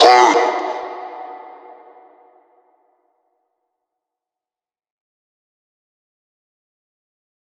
DMV3_Vox 3.wav